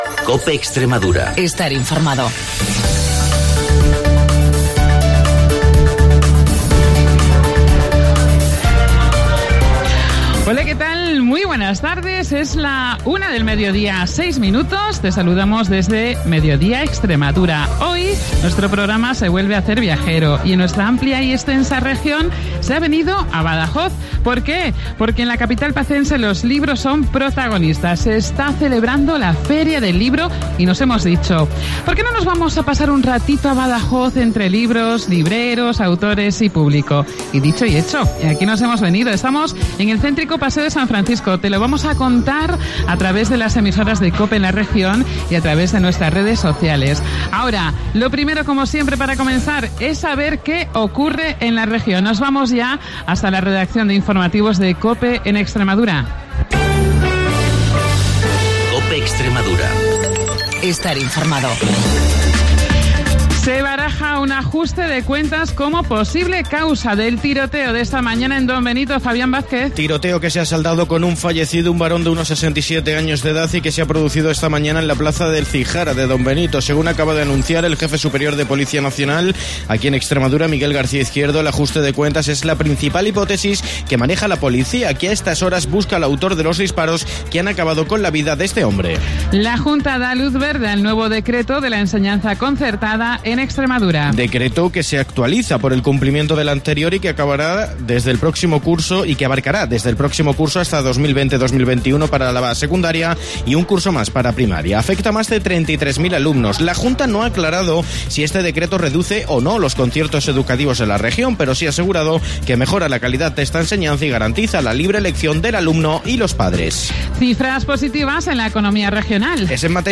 Hoy en directo desde la FERIA del LIBRO de BADAJOZ.